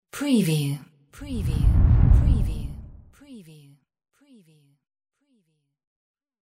Transition whoosh 23
Stereo sound effect - Wav.16 bit/44.1 KHz and Mp3 128 Kbps
previewTLFE_DISTORTED_TR_WBHD23.mp3